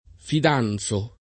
fidanzo [ fid # n Z o ]